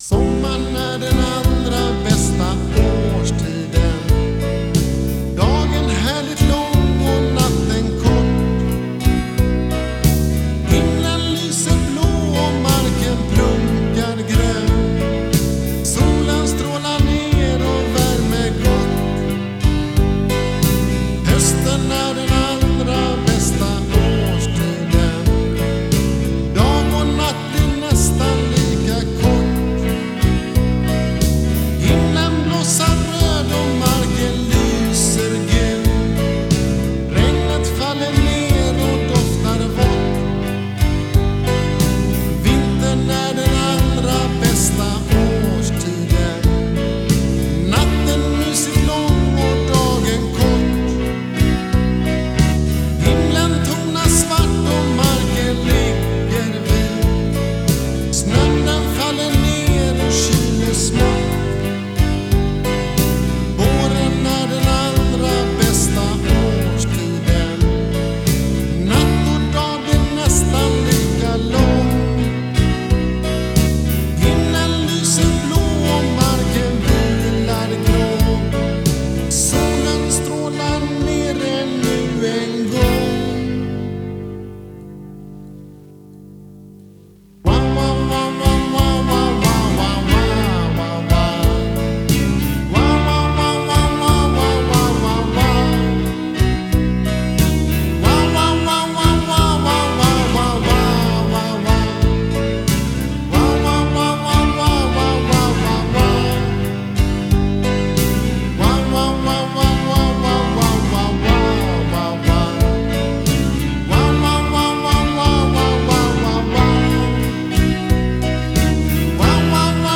Det är det udda B7-ackordet som gör följden.
Melodin visade sig vara ganska lik Stevie Wonders Lately.